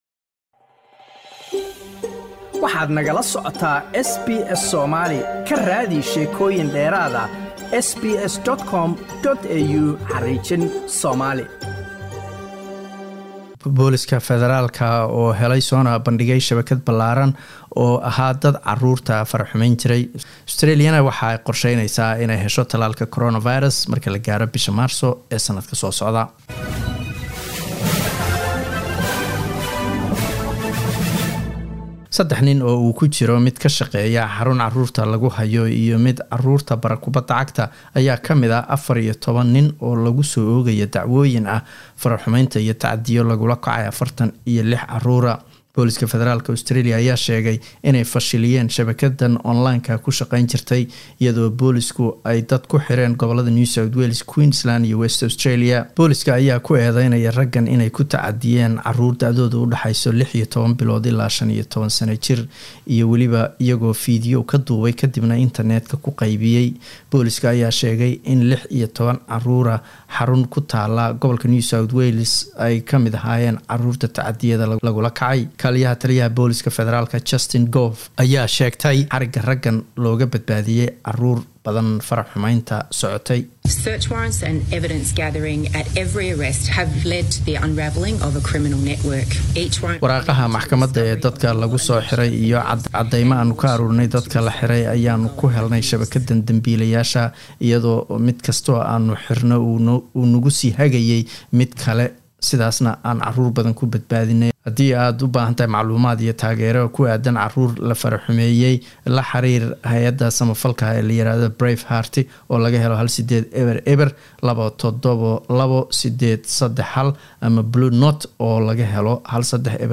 Wararka SBS Somali Arbaco 11 Nofember